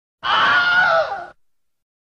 Wilhelm Scream - Gaming Sound Effect (HD)